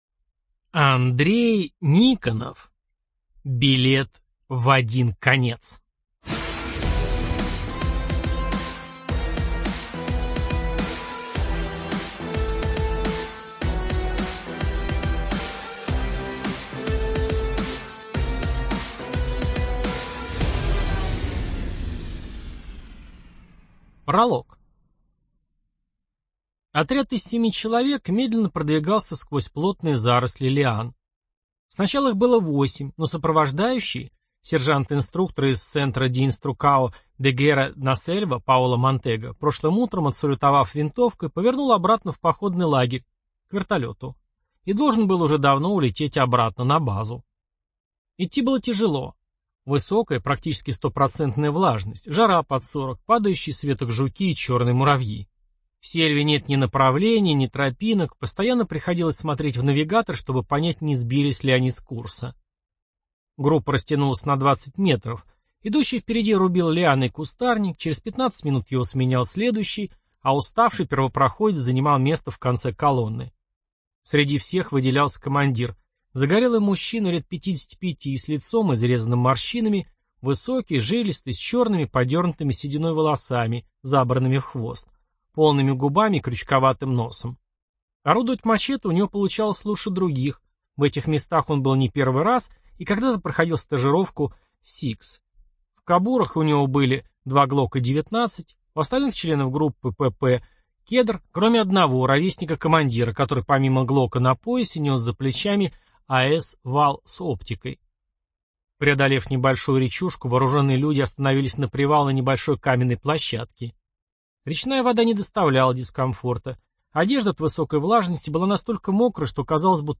ЖанрФантастика, Боевики, Фэнтези